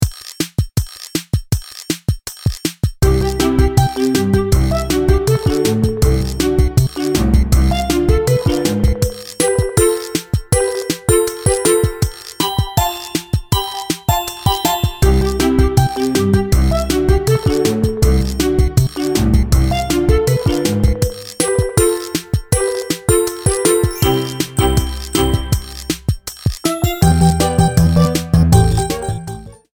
Trimmed, added fadeout